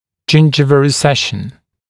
[‘ʤɪnʤɪvl rɪ’seʃn][‘джиндживл ри’сэшн]рецессия десны